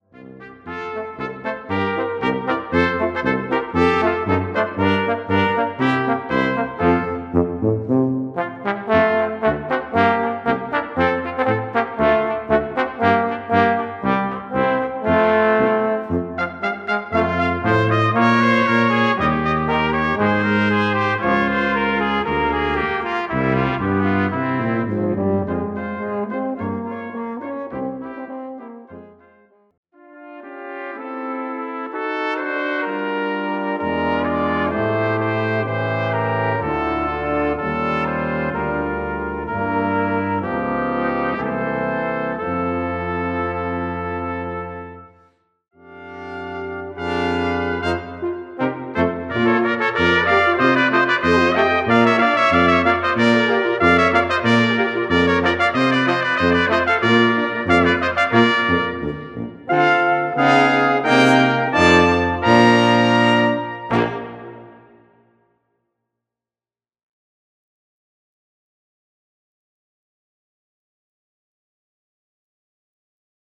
Gattung: für Blechbläserquintett
Besetzung: Ensemblemusik für 5 Blechbläser